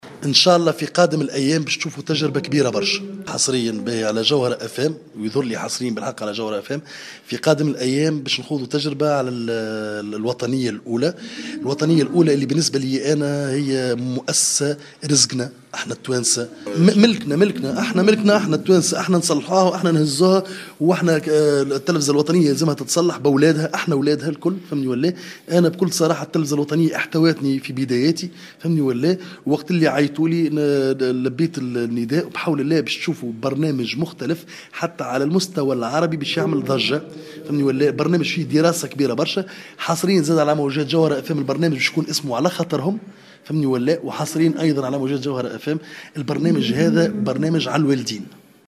قال الممثل الكوميدي جعفر القاسمي في تصريح للجوهرة "اف ام" إنه سيشارك في تجربة جديدة وكبيرة على القناة الوطنية في قادم الأيام .